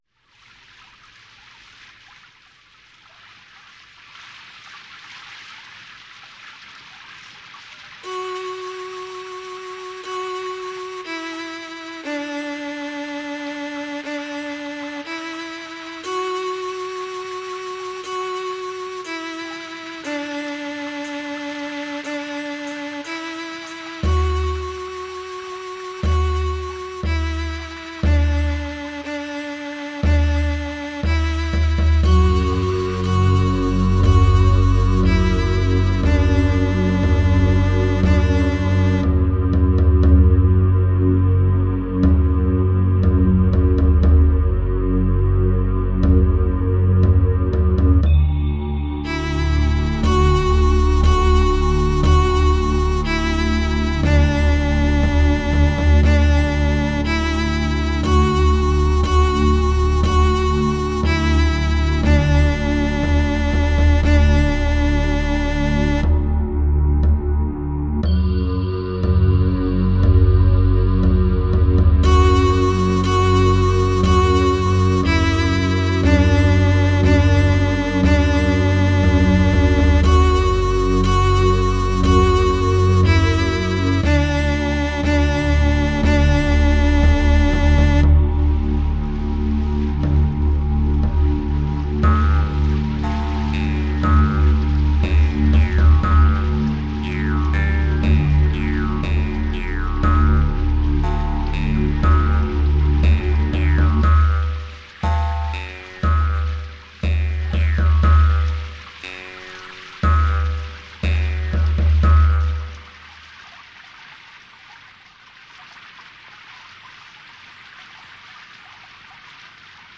A Russian folk song.